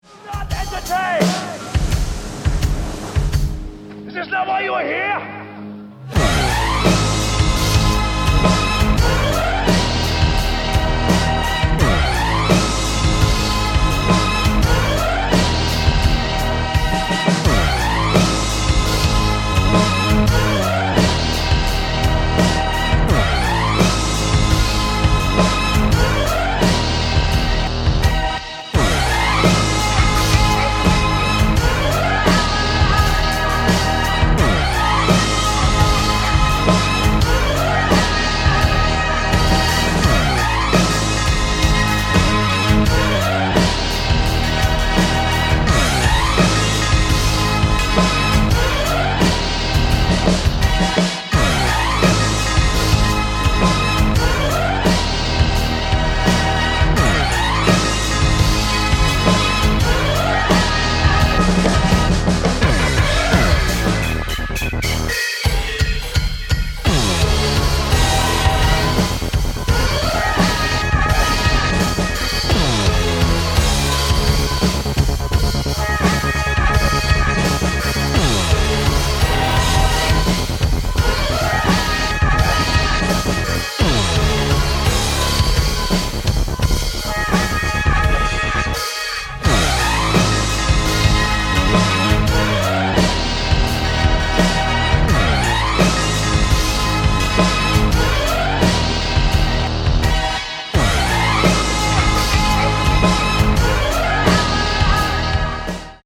Пример аудио-трэка в стиле Hip-hop Категория: Написание музыки
Пример трэка в стиле Hip-Hop/Dub Step